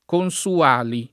vai all'elenco alfabetico delle voci ingrandisci il carattere 100% rimpicciolisci il carattere stampa invia tramite posta elettronica codividi su Facebook Consuali [ kon S u- # li ] n. pr. m. pl. stor. — antica festa romana